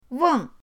weng4.mp3